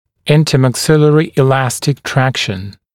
[ˌɪntəmæk’sɪlərɪ ɪ’læstɪk ‘trækʃn][ˌинтэмэк’силэри и’лэстик ‘трэкшн]межчелюстная эластичная тяга